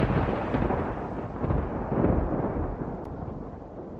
Thunder Crack
# thunder # crack # storm About this sound Thunder Crack is a free sfx sound effect available for download in MP3 format.
305_thunder_crack.mp3